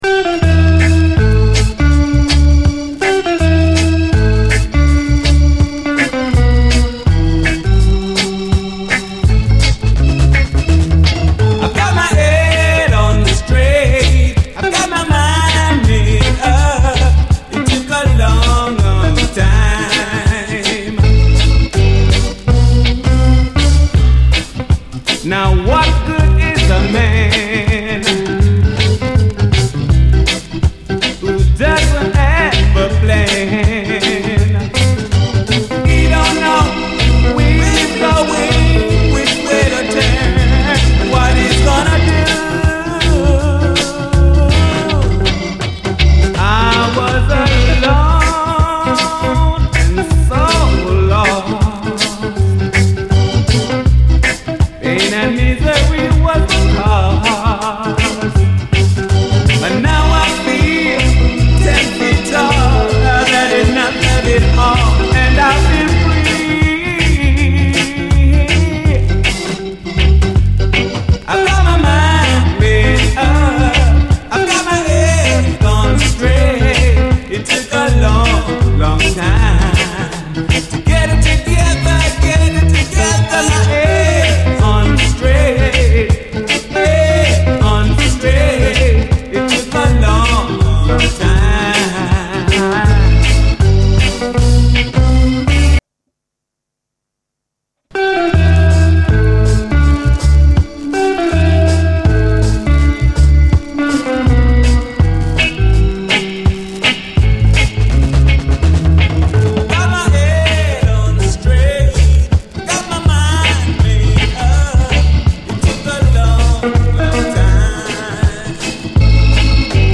REGGAE, 7INCH
最高の哀愁スウィート・レゲエ〜ラヴァーズ・ロック45！
• COUNTRY : UK